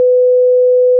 Lihtheli: 500 Hz siinusheli; ühe sekundiga tehakse 500 täisvõnget, 1 täisvõnkeks kulub 0,002 sekundit.
siinus_500Hz.wav